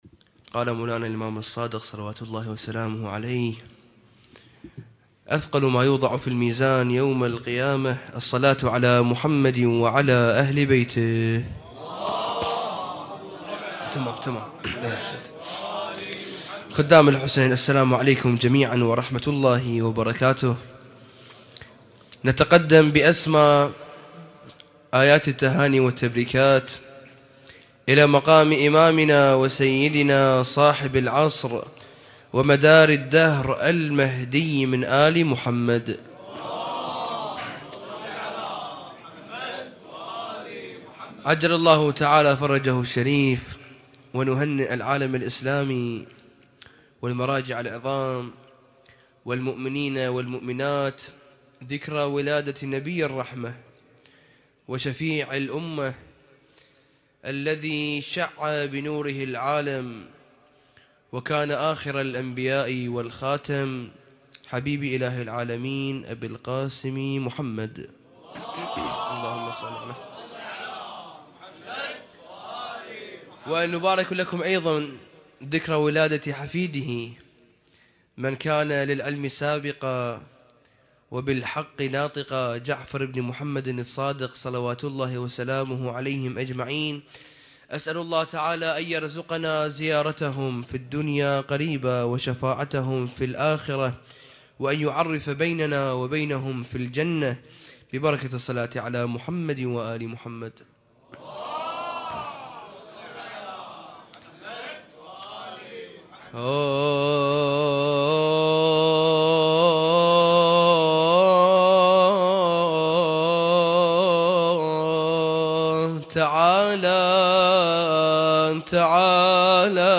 اسم النشيد:: مولد الرسول الأعظم والإمام الصادق عليها السلام
الرادود